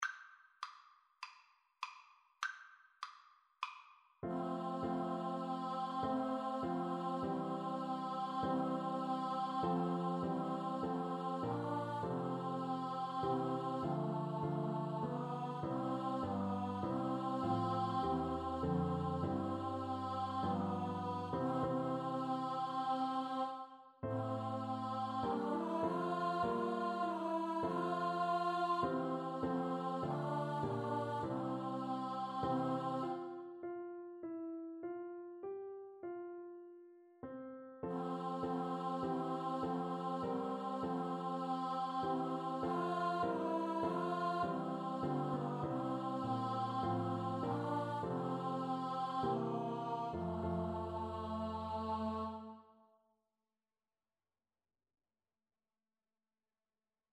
F major (Sounding Pitch) (View more F major Music for Choir )
4/4 (View more 4/4 Music)
Traditional (View more Traditional Choir Music)
o_come_PNO_kar2.mp3